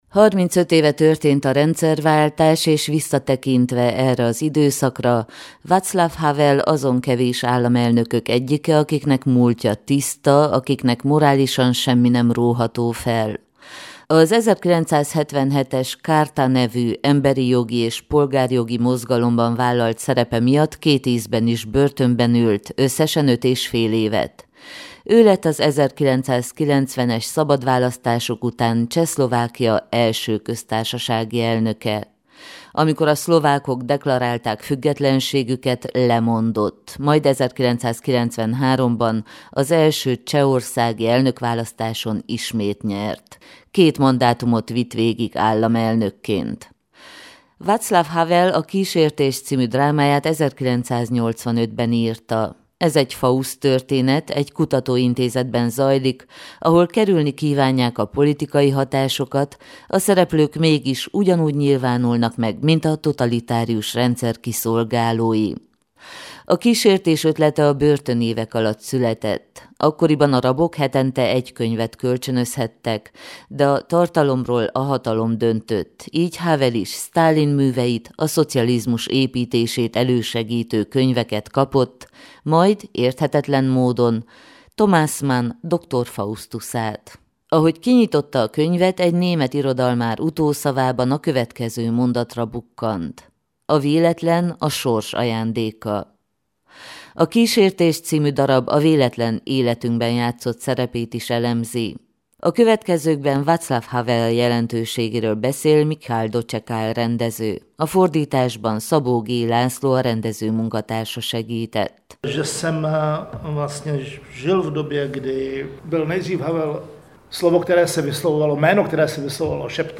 Az alkotók sajtótájékoztatón meséltek a készülő produkcióról.